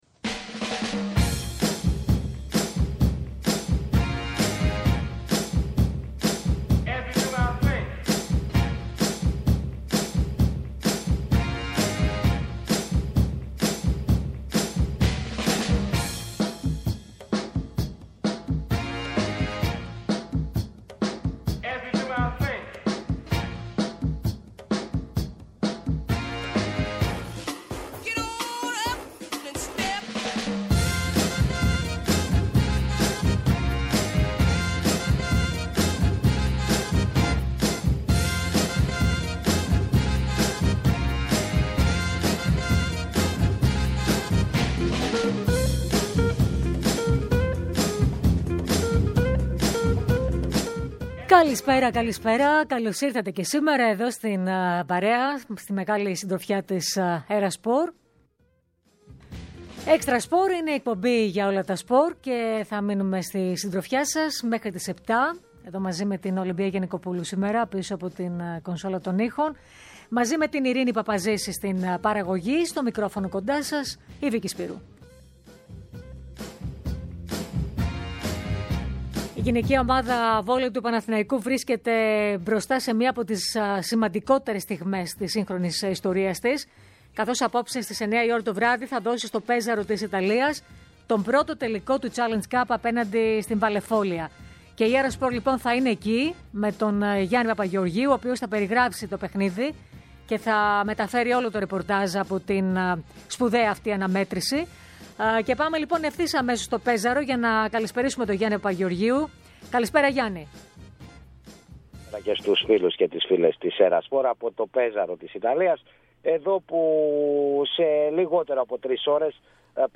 σε ζωντανή σύνδεση με την Ιταλία όπου μετέχει στους Χειμερινούς Παραολυμπιακούς αγώνες